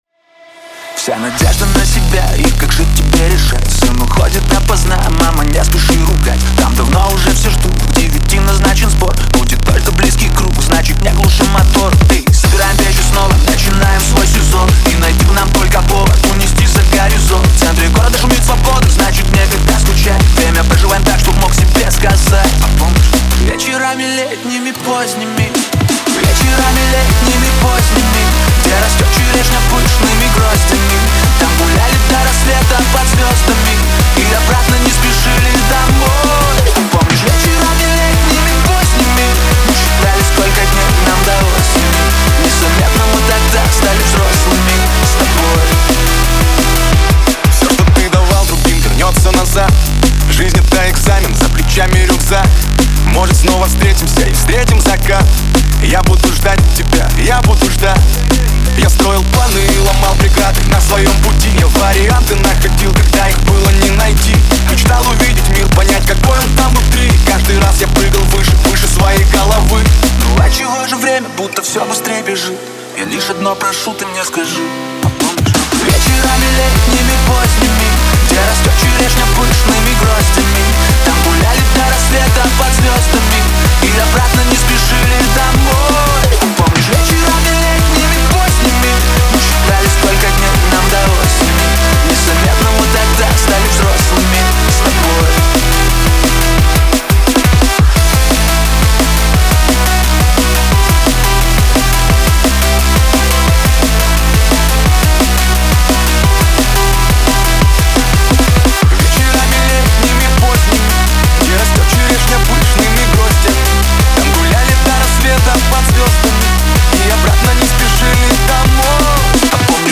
• Ремикс